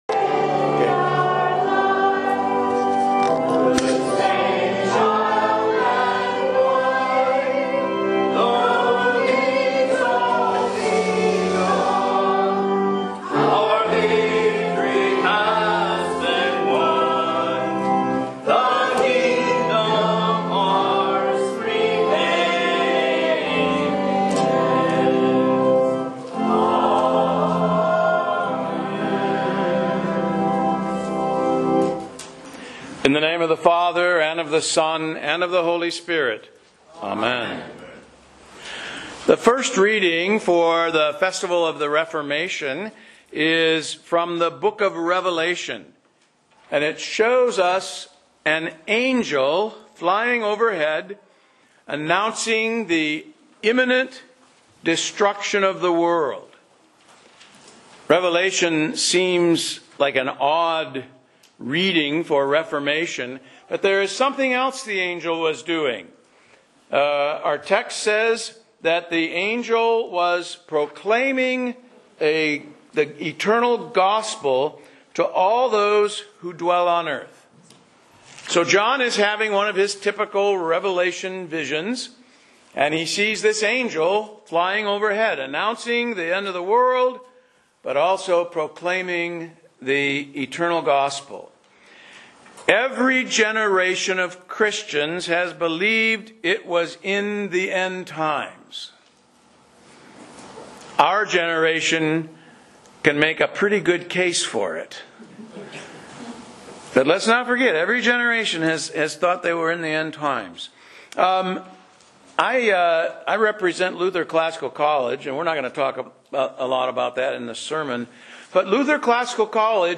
Sermons and Bible Classes